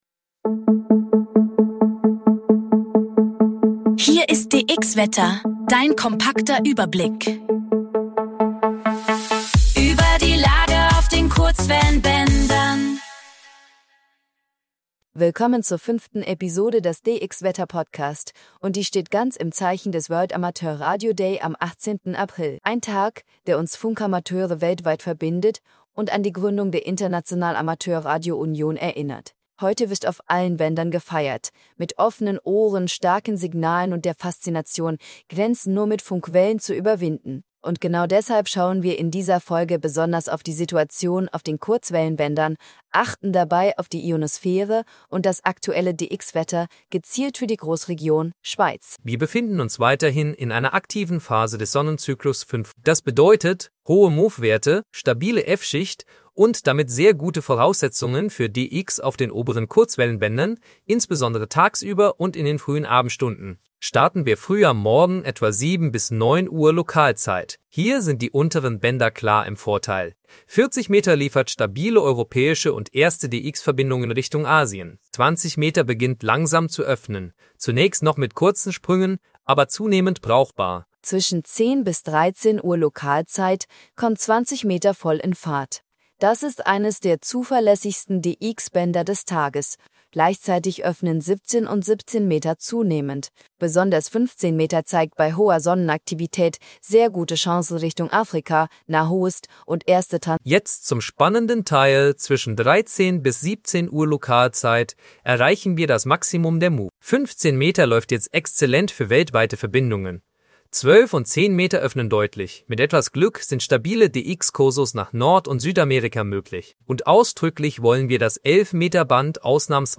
KI-generierter Inhalt - aktuell für die Region SCHWEIZ.